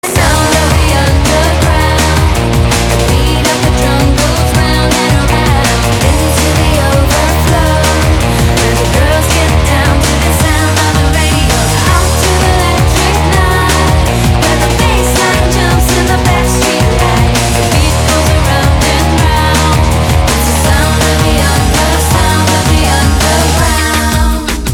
• Качество: 320, Stereo
поп
громкие
женский вокал
Electropop
в стиле британской гаражной музыки